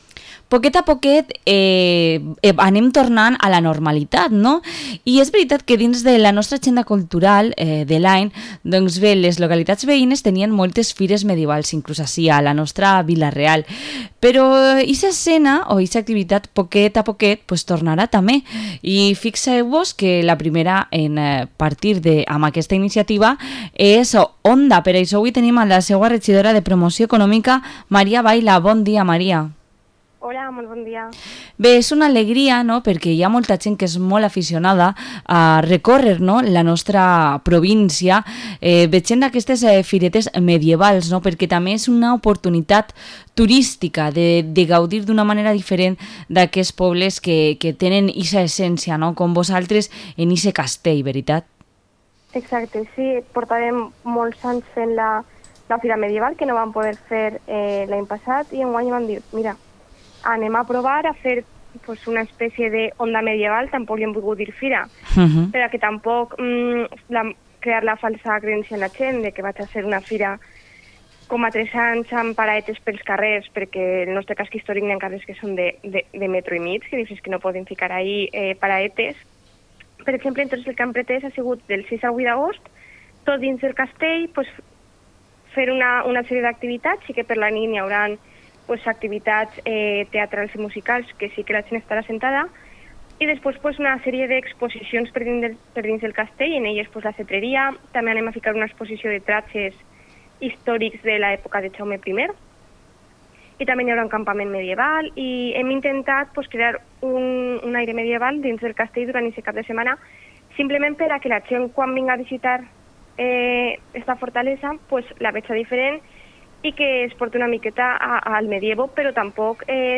Entrevista a la concejala de Promoción Económica de Onda, María Baila